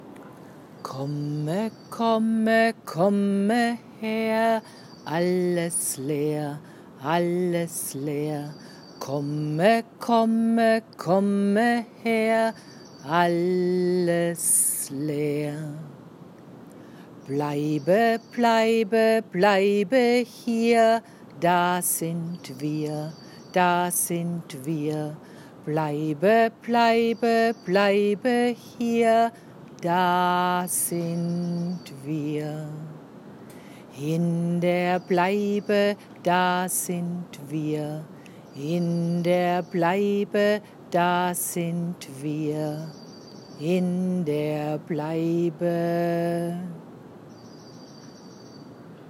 TreeBleibe chant